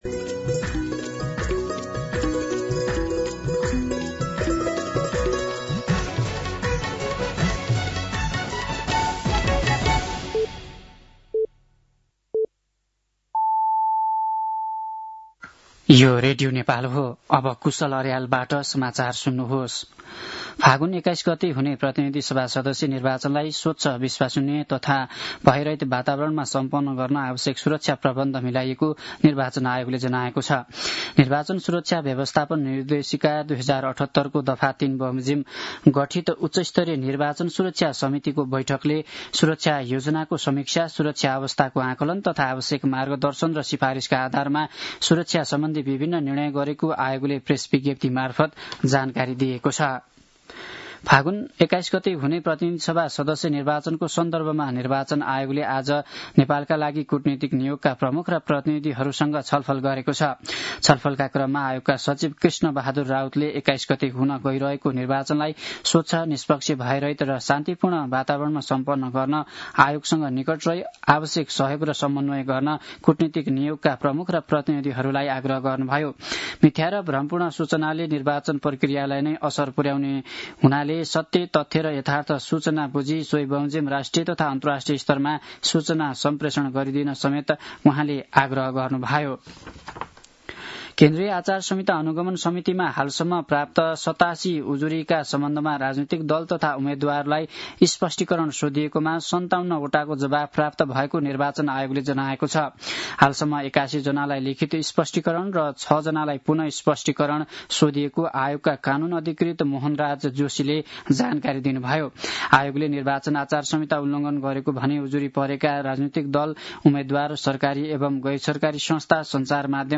दिउँसो ४ बजेको नेपाली समाचार : १४ फागुन , २०८२